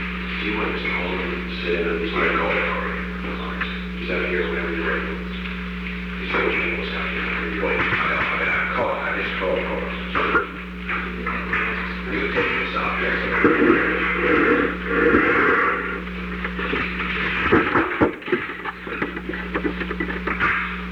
Secret White House Tapes
Conversation No. 538-14
Location: Oval Office